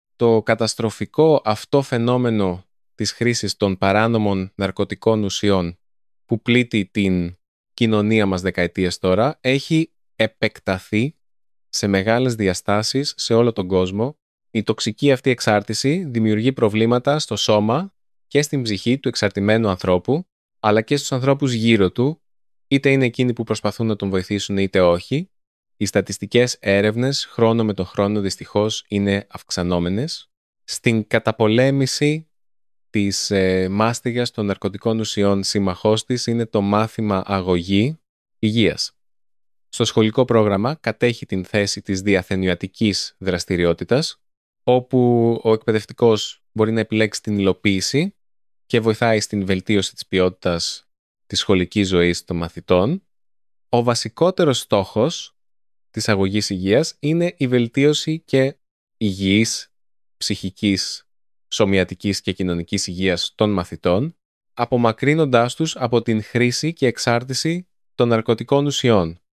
Για εργαλειο προσβασιμοτητας εχω μετατρεψει τον πρωτο εκπαιδευτικο πορο απο κειμενο σε ηχο.
Το εργαλείο Text-to-Speech (μετατροπή κειμένου σε ομιλία) ενισχύει σημαντικά την προσβασιμότητα, γιατί επιτρέπει στον/στην εκπαιδευόμενο/η να ακούει τον εκπαιδευτικό πόρο αντί να τον διαβάζει.